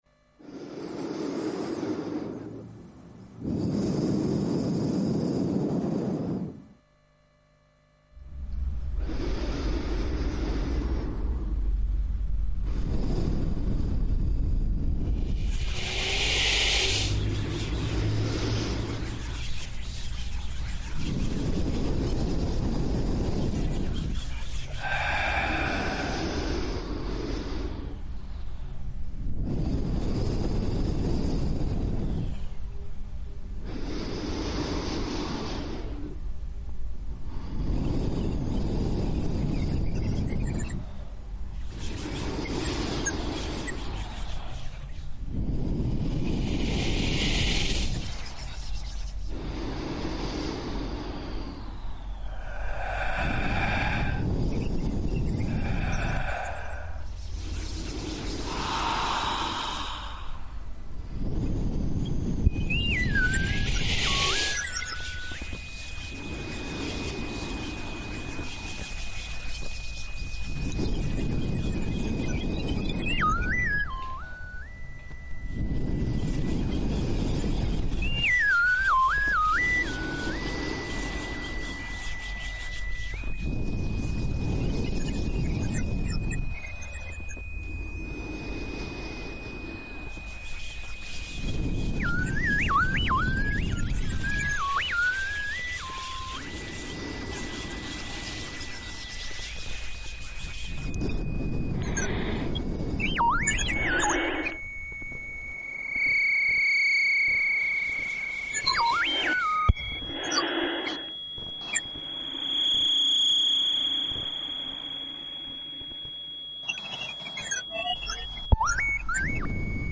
sculpture sonore